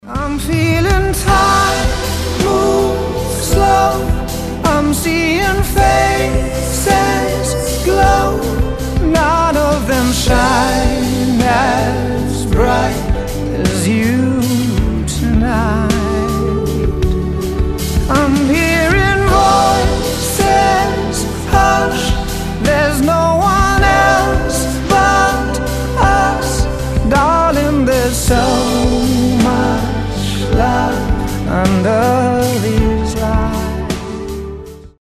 • Качество: 256, Stereo
поп
мужской вокал
романтичные